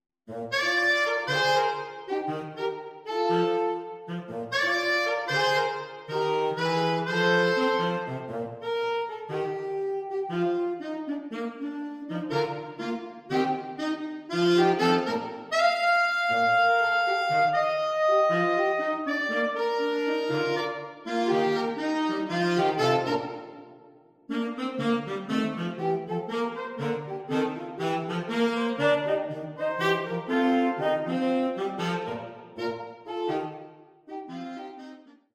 Traditional Folk